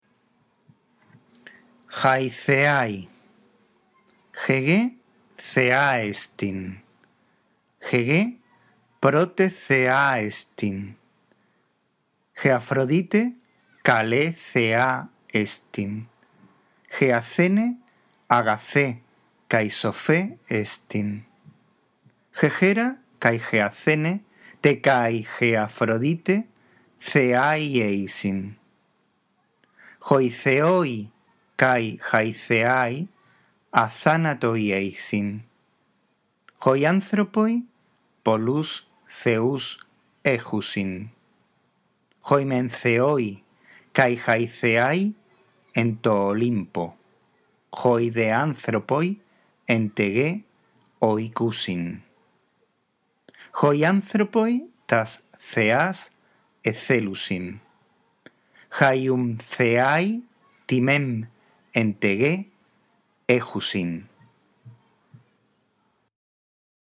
A.1. Lee, en primer lugar, despacio y en voz alta el texto.